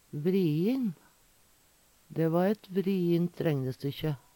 vriin - Numedalsmål (en-US)